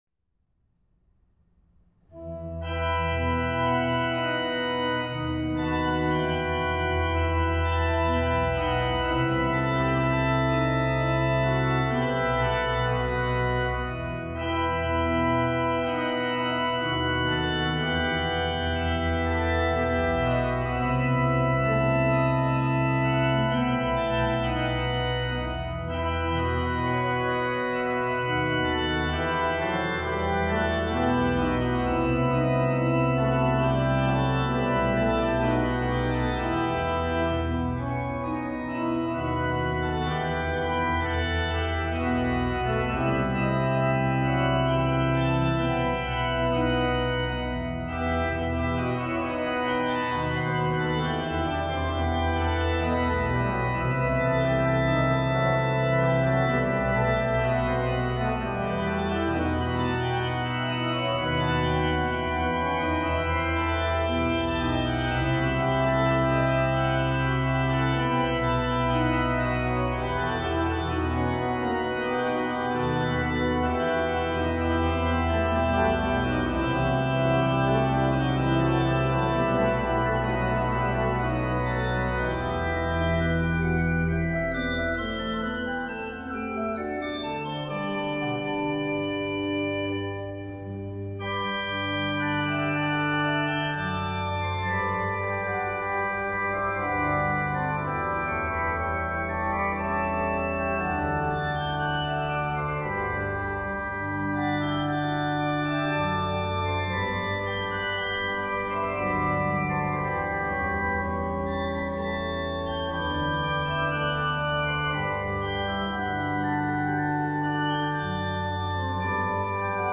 Passacaglia d-moll für Orgel